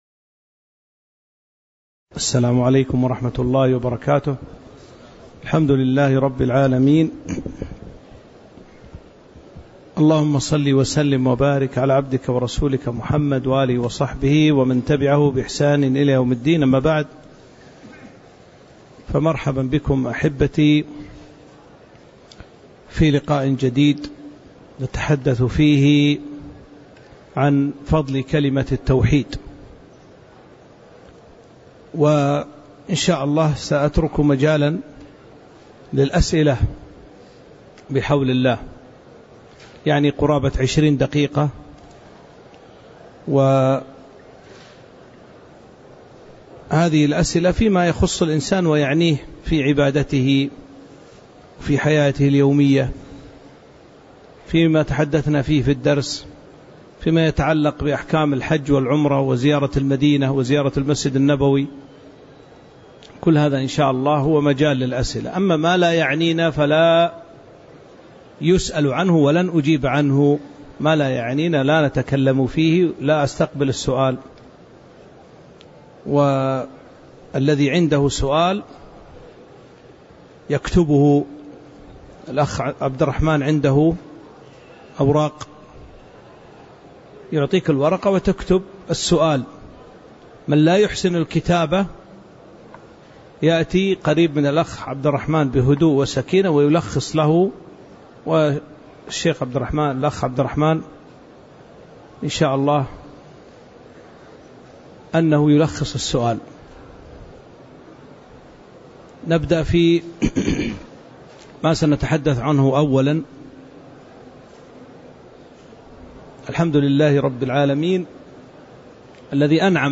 تاريخ النشر ١٩ ذو القعدة ١٤٤٥ هـ المكان: المسجد النبوي الشيخ